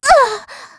Estelle-Vox_Damage_kr_03.wav